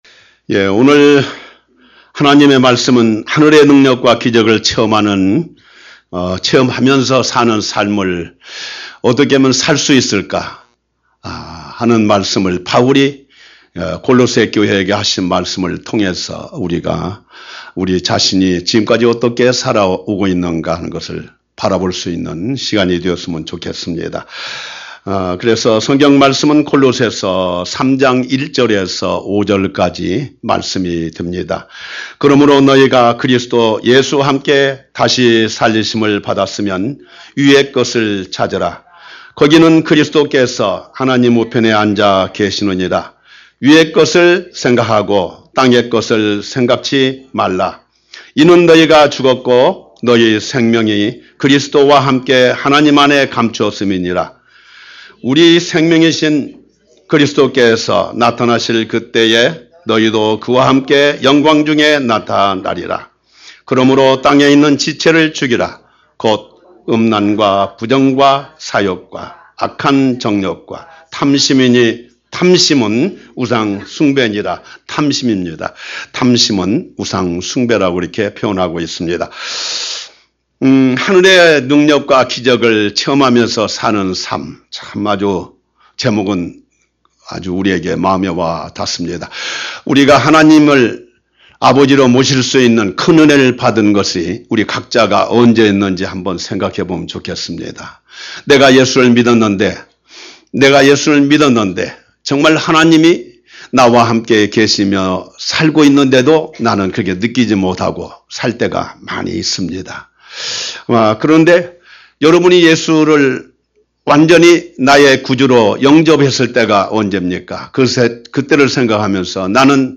Sermon - 하늘의 능력과 기적을 체험하며 사는 삶